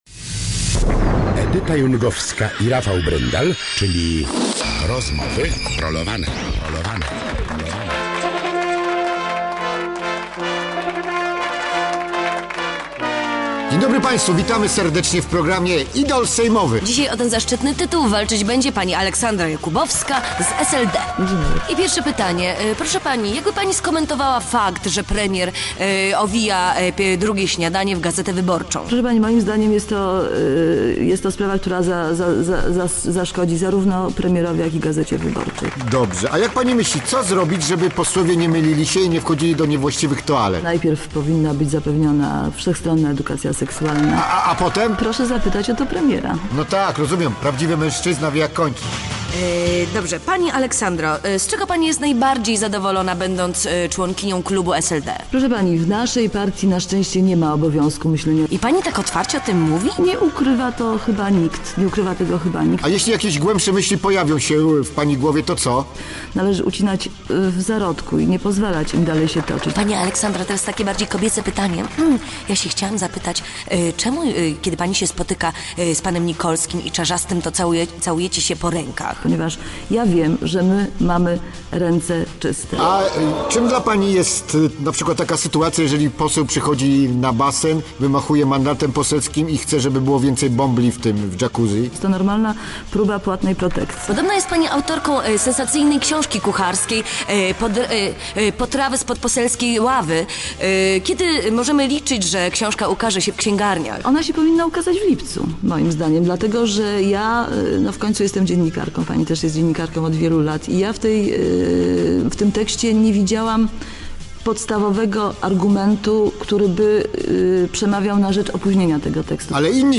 Aleksandra Jakubowska odpowiada na pytania Edyty Jungowskiej i Rafała Bryndala w audycji Rozmowy Rolowane.